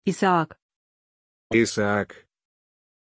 Aussprache von Isaak
pronunciation-isaak-ru.mp3